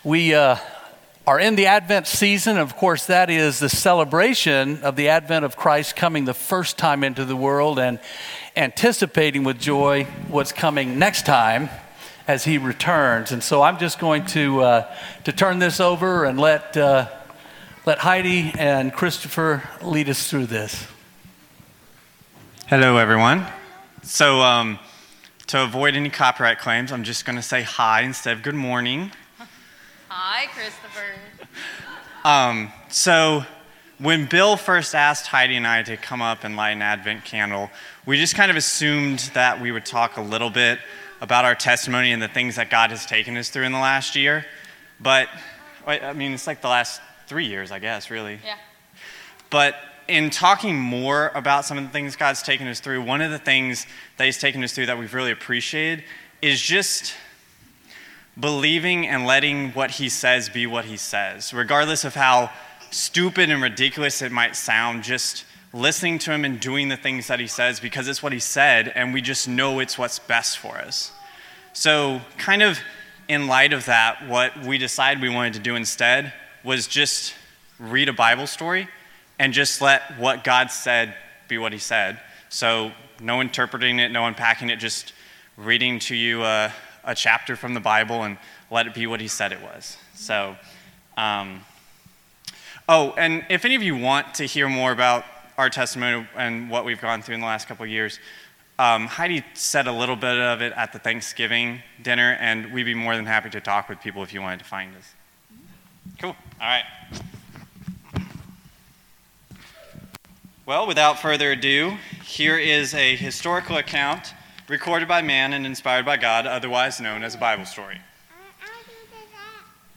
Testimony